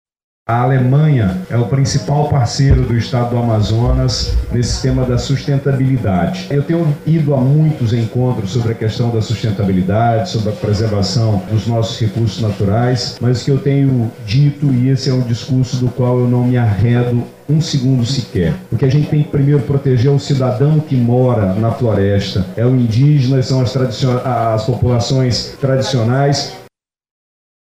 Sonora-Wilson-Lima-governador-do-Amazonas.mp3